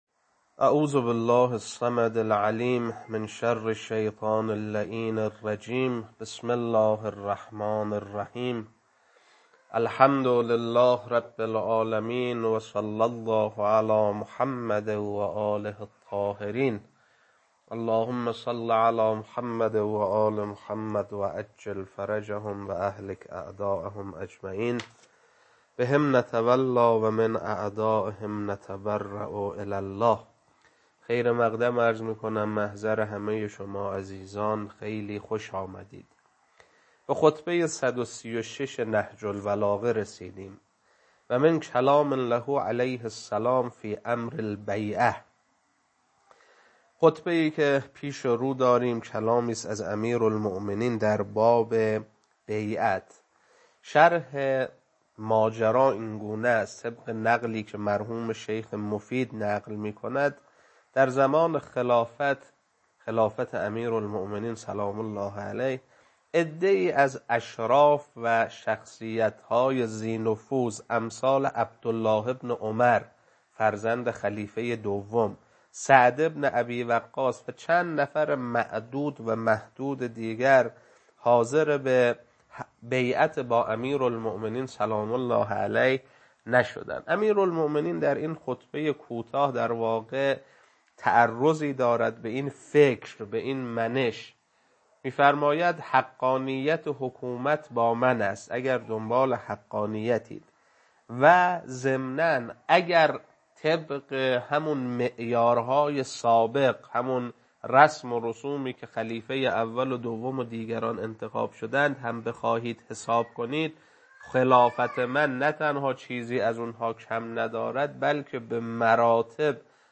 خطبه-136.mp3